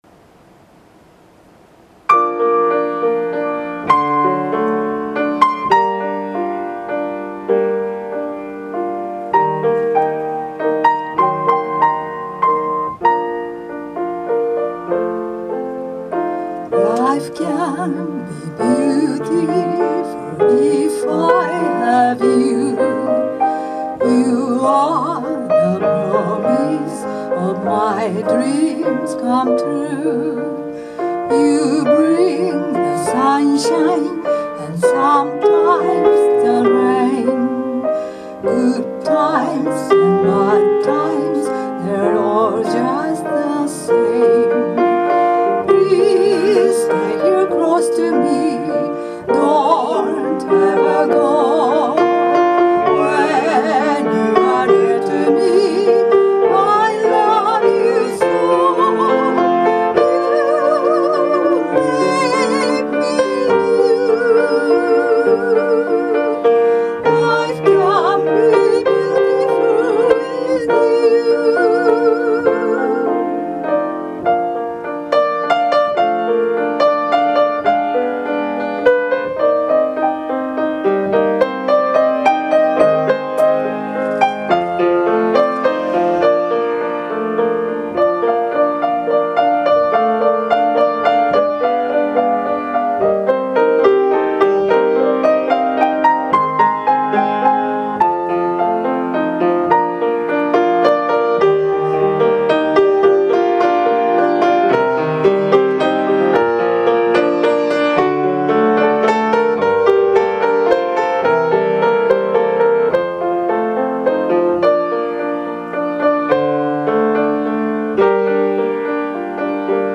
テストのため敢えて速いテンポで歌唱 & 演奏した。